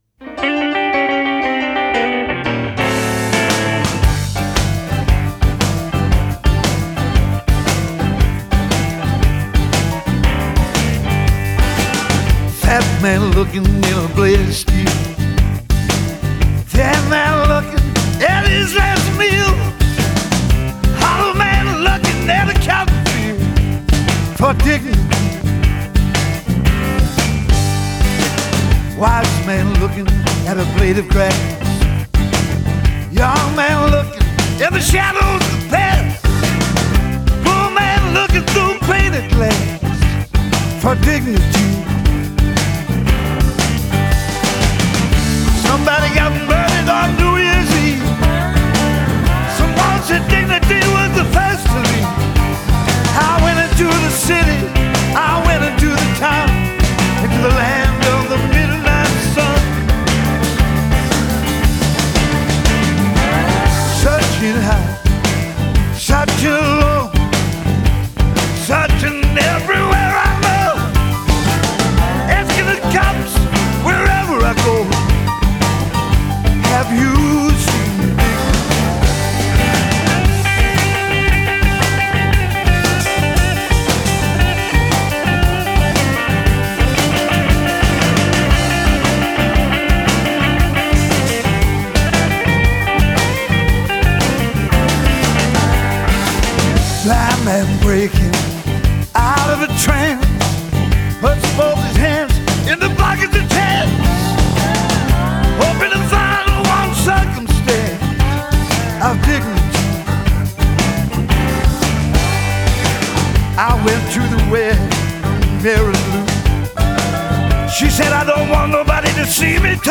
характерным хриплым вокалом